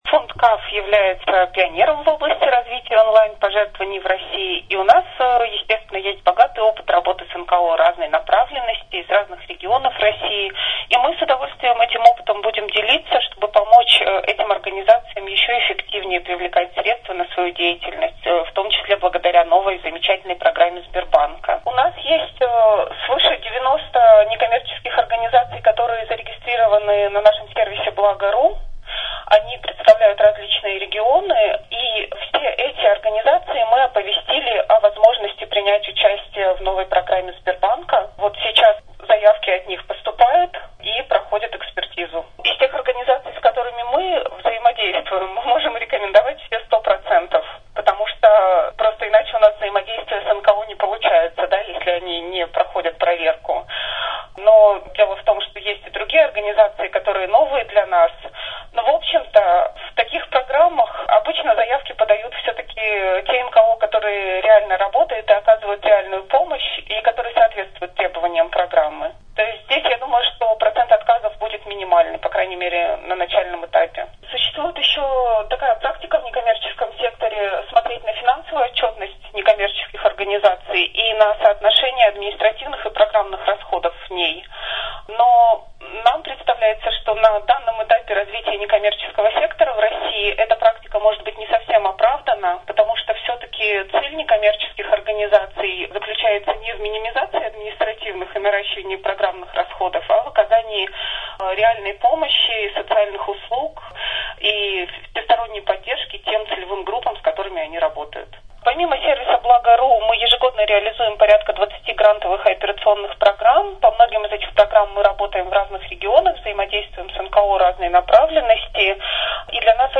Интервью
«Расскажем» — аудиопроект АСИ: живые комментарии экспертов некоммерческого сектора на актуальные темы.